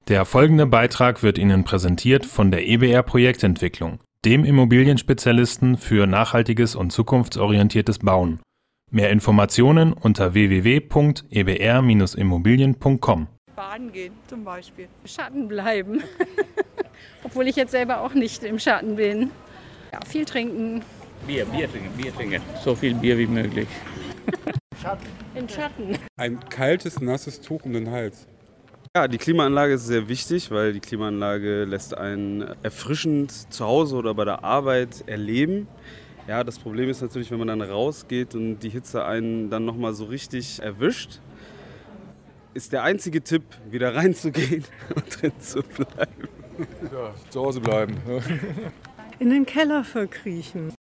Wir wollten von den Göttingerinnen und Göttingern wissen, ob ihnen noch der ein oder andere Tipp gegen die Hitze einfällt.